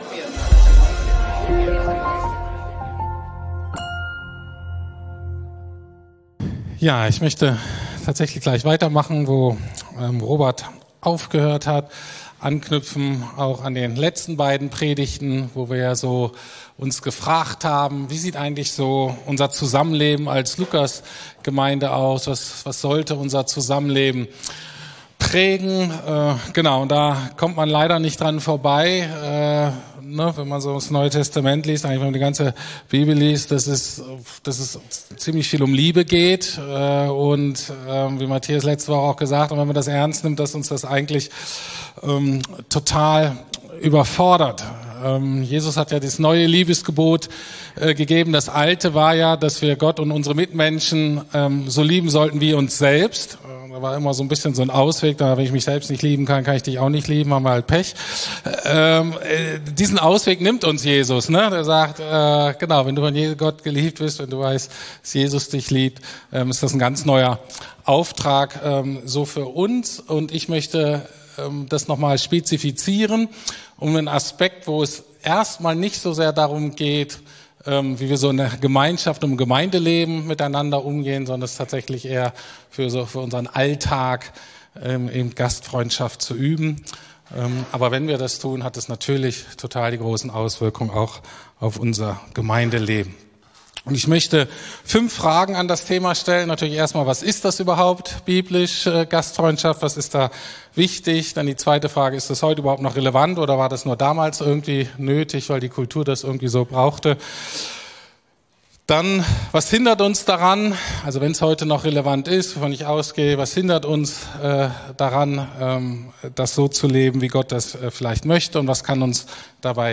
Liebe den Fremden ~ Predigten der LUKAS GEMEINDE Podcast